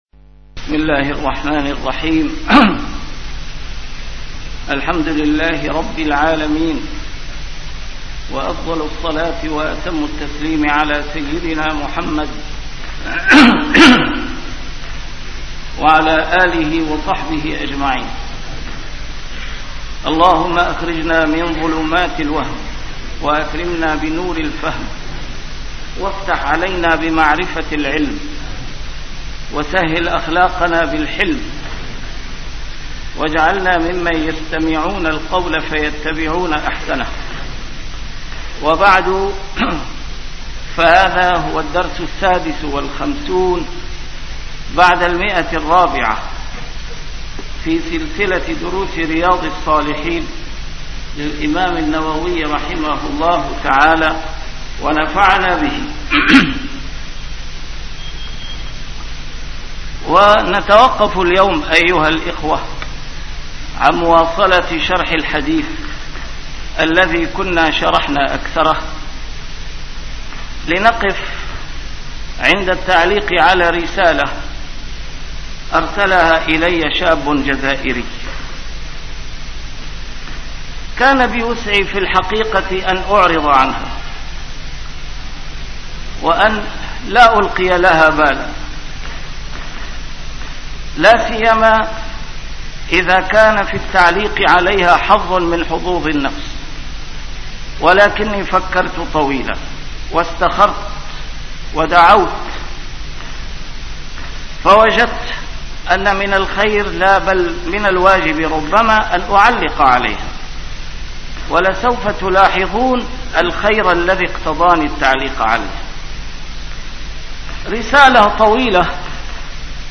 نسيم الشام › A MARTYR SCHOLAR: IMAM MUHAMMAD SAEED RAMADAN AL-BOUTI - الدروس العلمية - شرح كتاب رياض الصالحين - 456- شرح رياض الصالحين: الحكم بالظاهر (الجزائر)